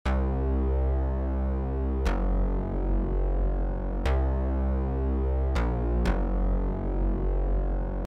First, choose waveform 8 on oscillator 1 and put the pitch up 10 cents. This will create a phase or beat when we turn the second oscillator on because of the difference in frequency. Next, turn on oscillator 2 and change the waveform to a sine wave, drop the octave to 3 and finally turn the FM up to 8.
bass1.mp3